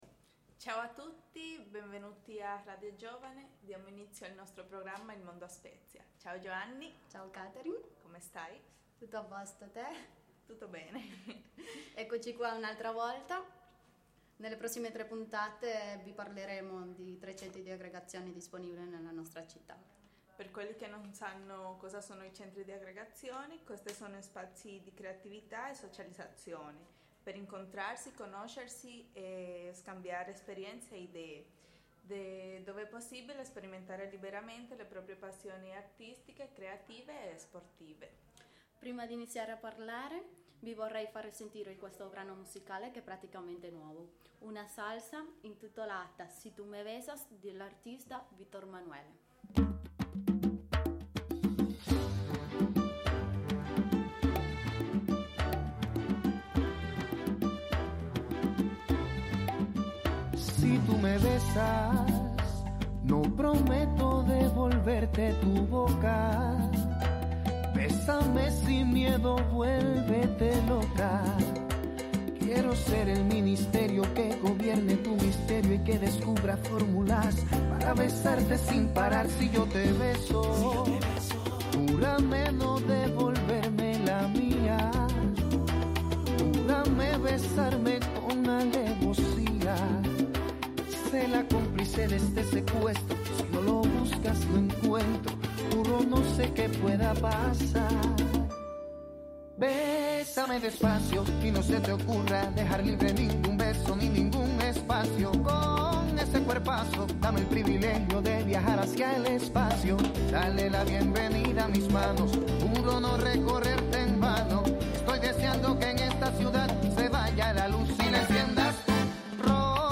Le conduttrici raccontano uno dei luoghi di aggregazione della città l?oratorio don Bosco